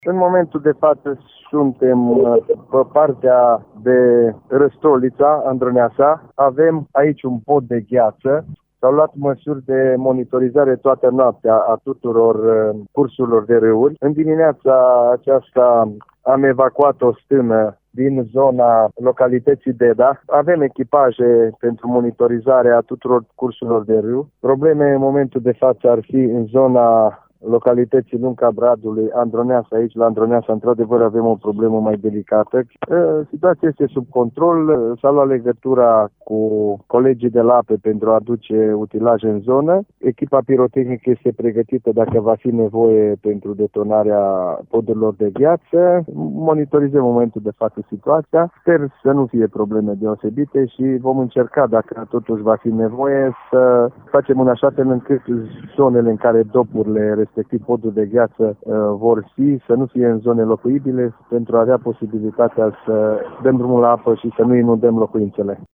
Şeful Inspectoratului pentru Situaţii de Urgenţă ”Horea” al judeţului Mureş, generalul Dorin Oltean: